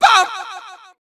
baBumBumBum_Farthest3.wav